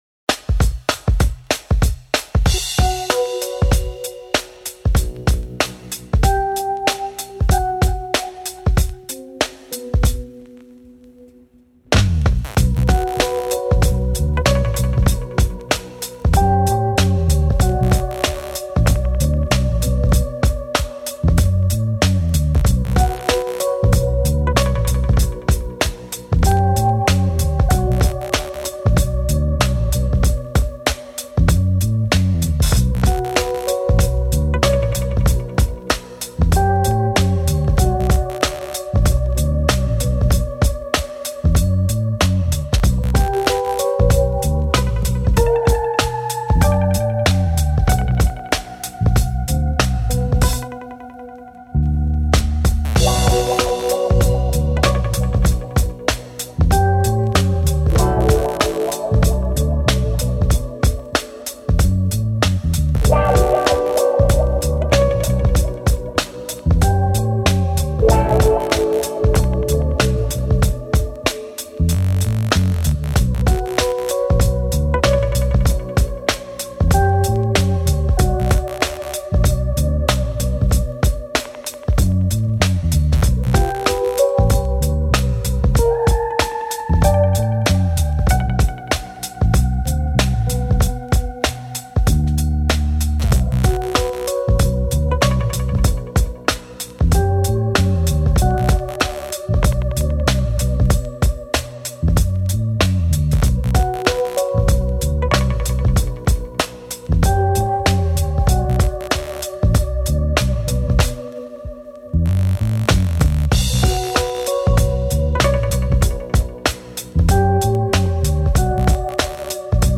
Funky, haunting hip hop beat.